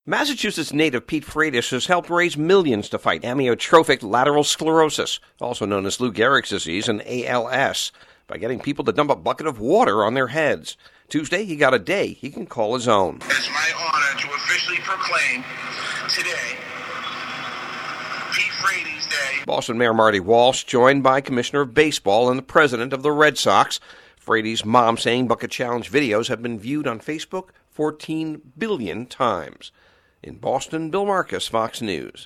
FOX NEWS RADIO’S